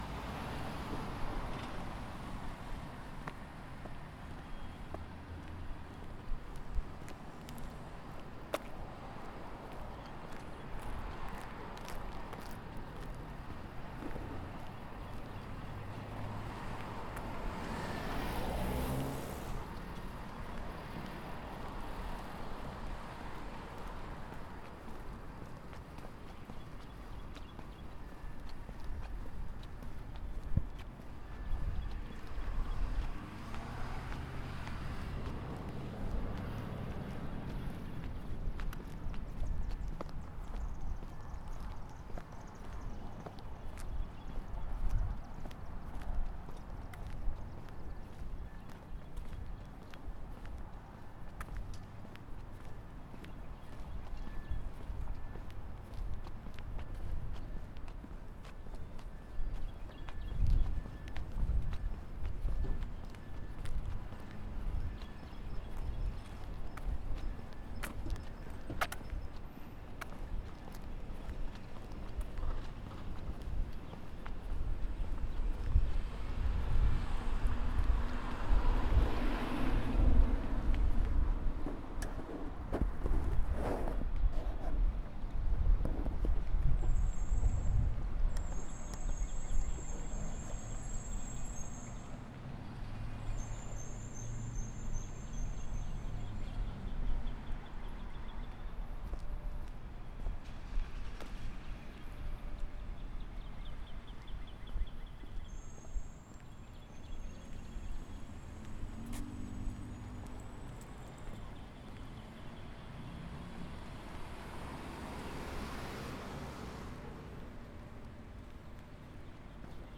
walking around town on a sunny Friday afternoon. Cars drive by, pedesrtains walk and talk and a fountain flows